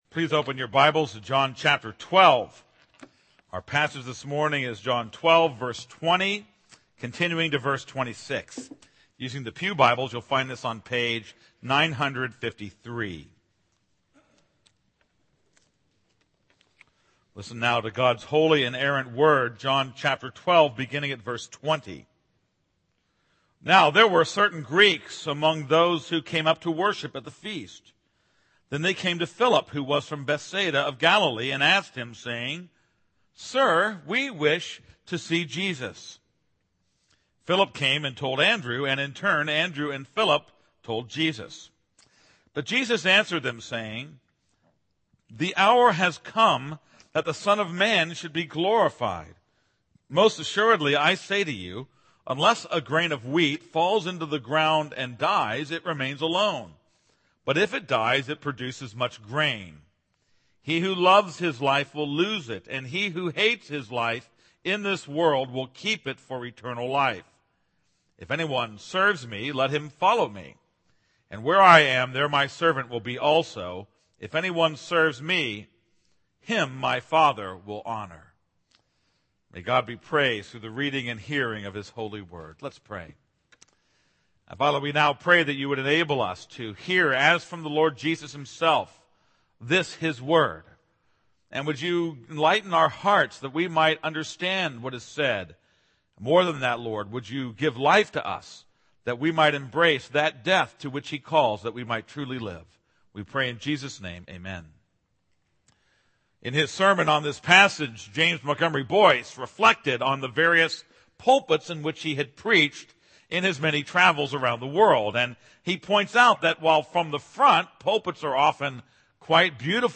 This is a sermon on John 12:20-26.